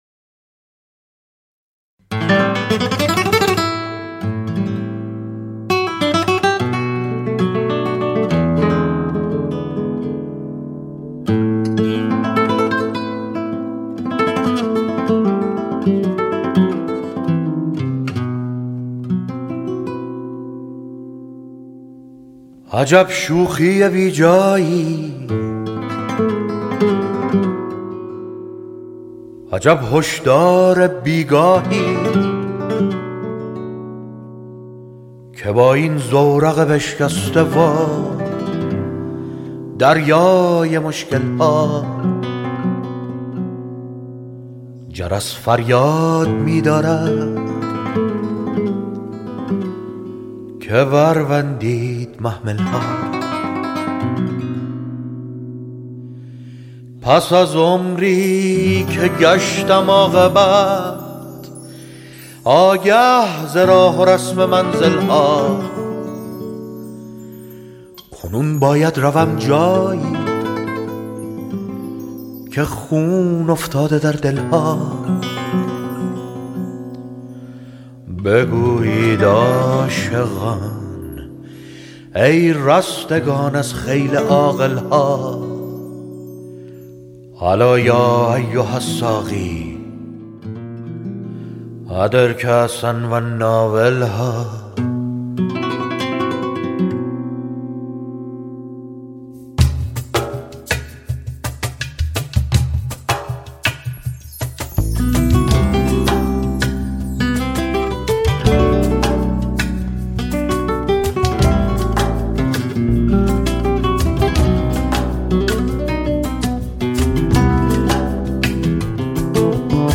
پاپ عرفانی